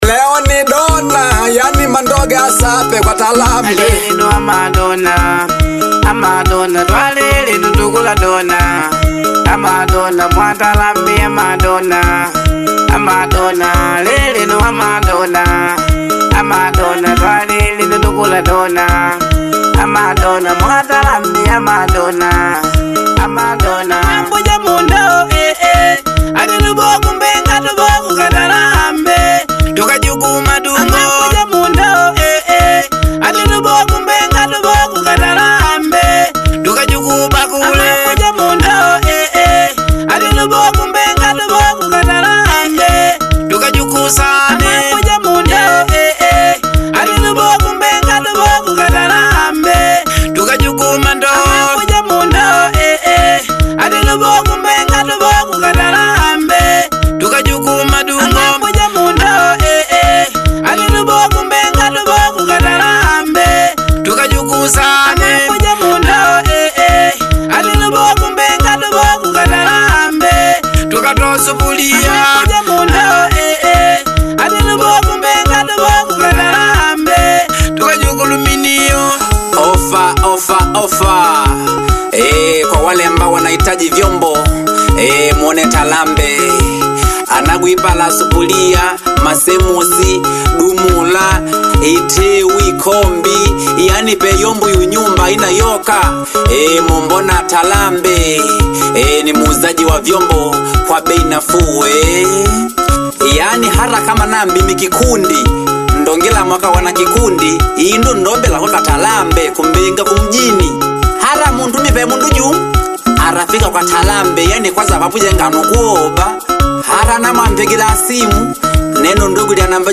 a high-energy track